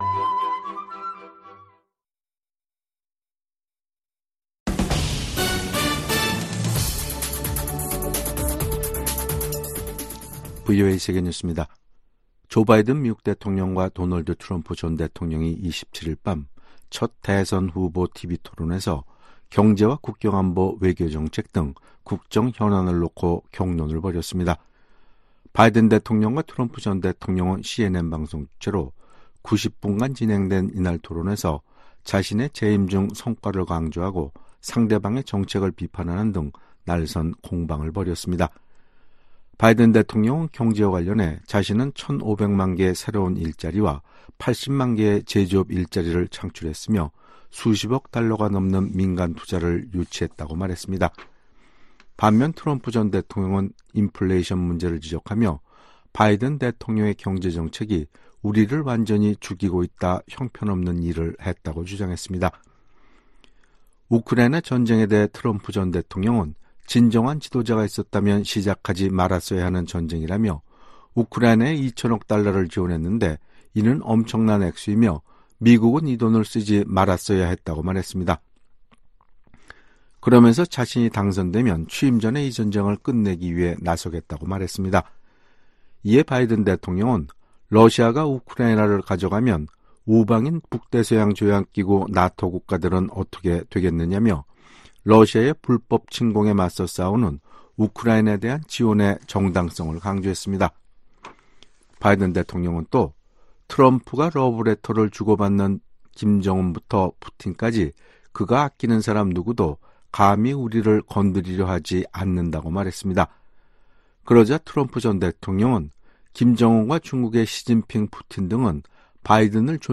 VOA 한국어 간판 뉴스 프로그램 '뉴스 투데이', 2024년 6월 28일 2부 방송입니다. 조 바이든 대통령과 도널드 트럼프 전 대통령이 첫 대선 후보 토론회에 참석해 날선 공방을 벌였습니다. 미국 정부는 한국 정치권에서 자체 핵무장론이 제기된 데 대해 현재 한국과 공동으로 확장억제를 강화하고 있다고 강조했습니다. 미 국무부 고위 관리가 최근 심화되고 있는 북한과 러시아 간 협력에 대한 중대한 우려를 나타냈습니다.